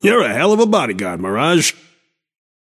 Shopkeeper voice line - You’re a hell of a bodyguard, Mirage.